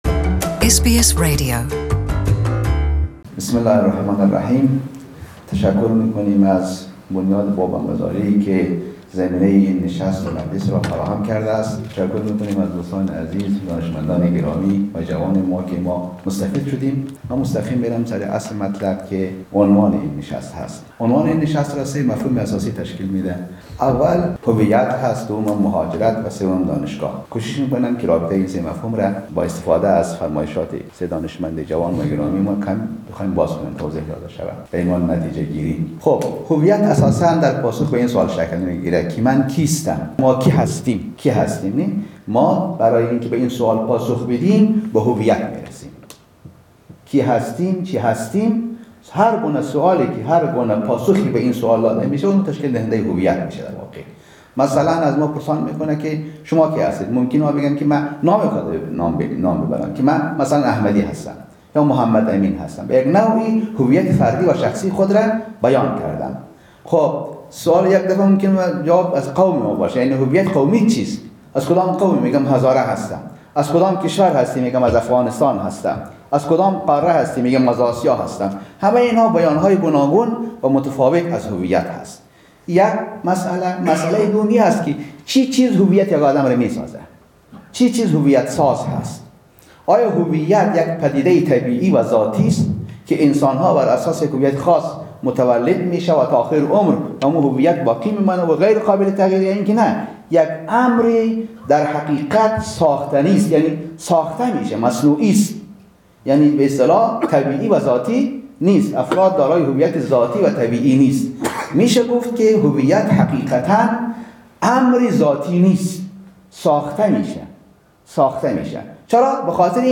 در یک نشست علمی بررسی روابط «هویت، مهاجرت و دانشگاه» در ملبورن سخنرانی می‌کرد